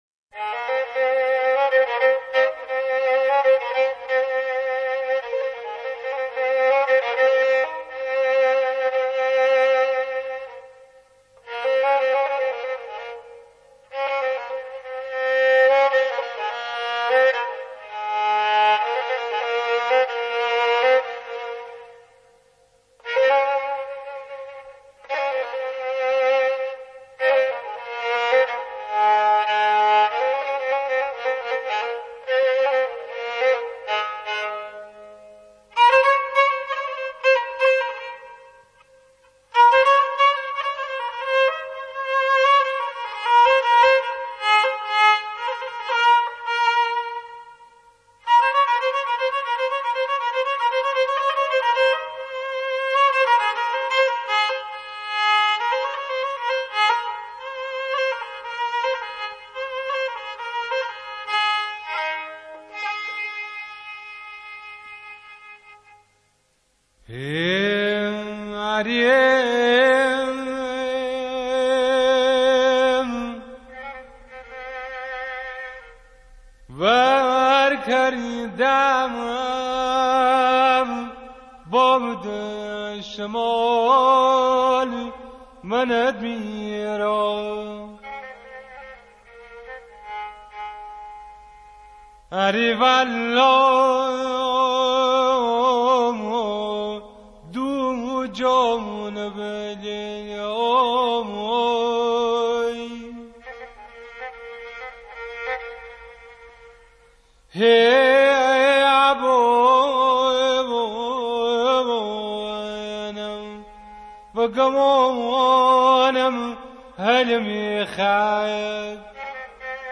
06-saz-o-avaz.mp3